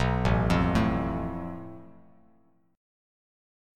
Asus2#5 chord